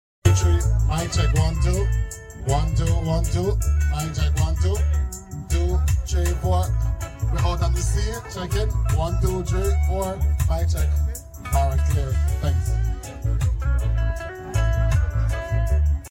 mic check 1 2 sound effects free download
You Just Search Sound Effects And Download. tiktok sound effects funny Download Sound Effect Home